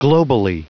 Prononciation du mot globally en anglais (fichier audio)
Prononciation du mot : globally